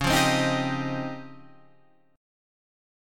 DbM#11 chord